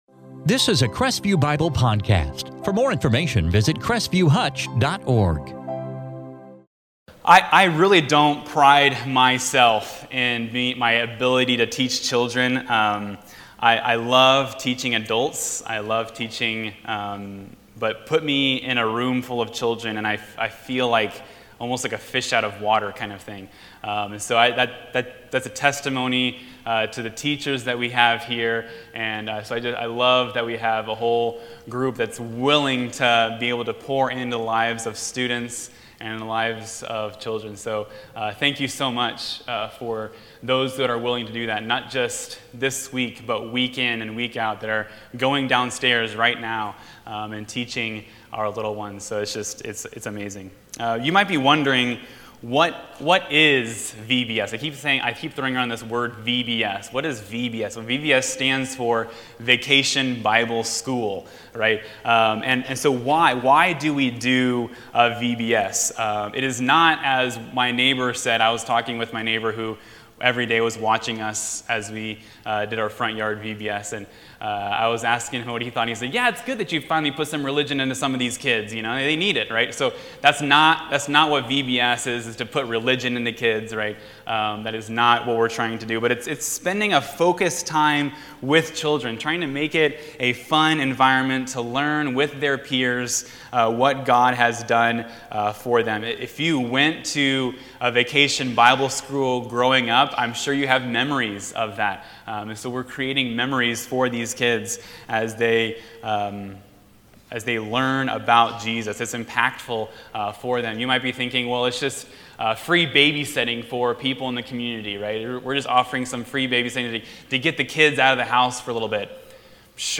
2022 Stand Alone Sermons 1 Samuel Transcript On Sunday